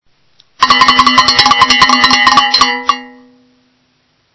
Cowbells
The cowbell is a hand percussion musical instrument used in a variety of different styles of music.
901200 Cowbell (white)
Cow_bell.MP3